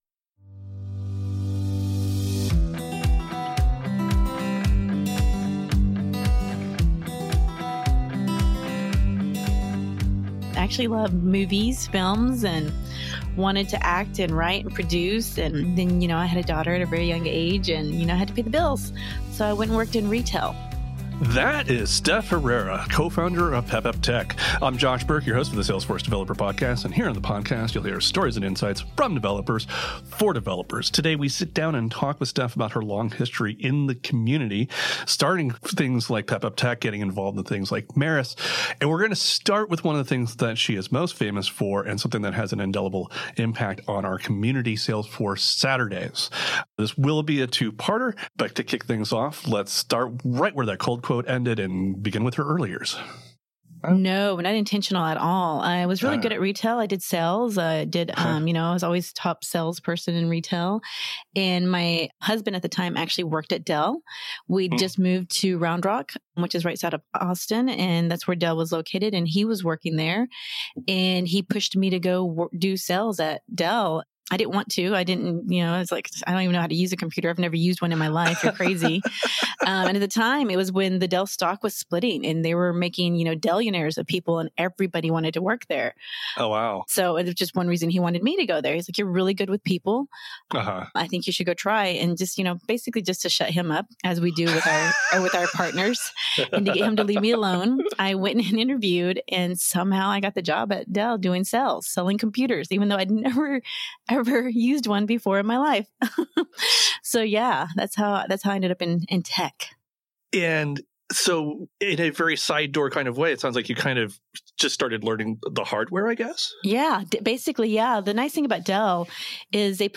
We then discuss MuleSoft APIs with Salesforce's Einstein Copilot. Don't miss out on this dialogue filled with the excitement of what's to come as we integrate Data Cloud, MuleSoft, and Einstein Copilot.